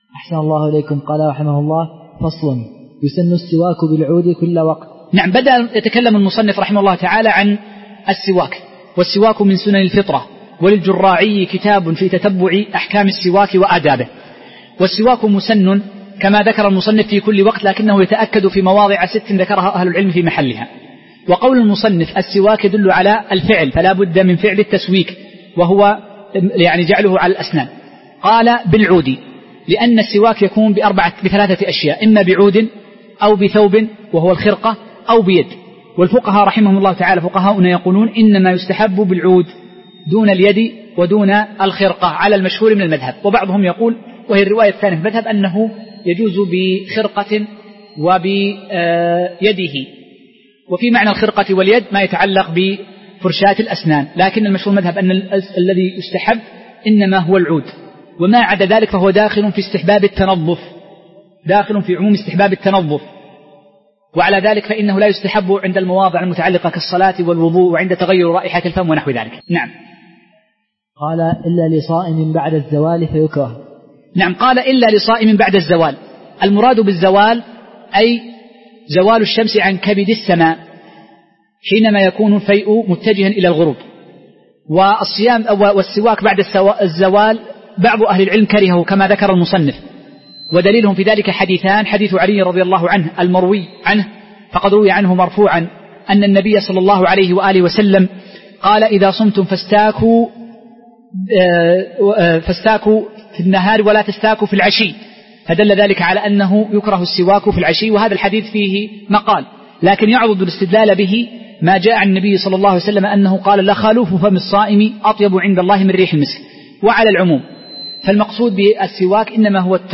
شرح أخصر المختصرات في الفقه الحنبلي الدرس 2